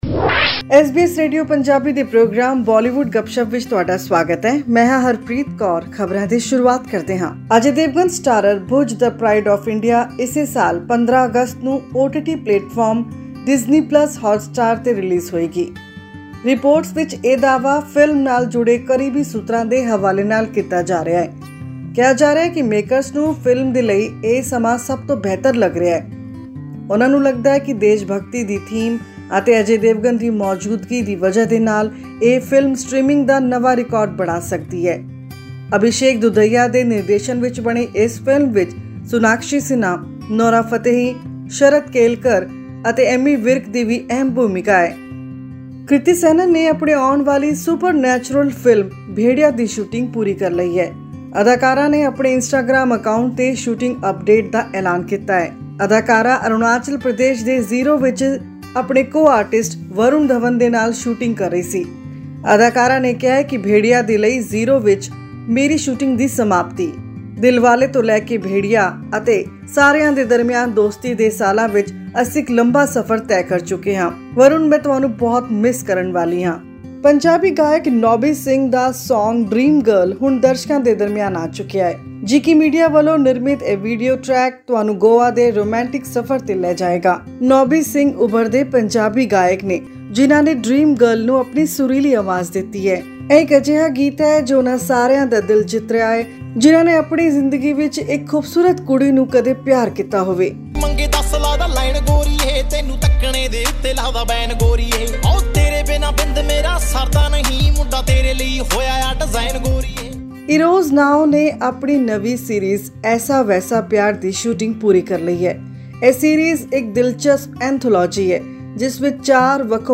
In other news, music composer Shravan Rathod, of popular music director duo Nadeem-Shravan, passed away last week due to COVID-19 related complications. Click on the player at the top of the page to listen to the news bulletin in Punjabi.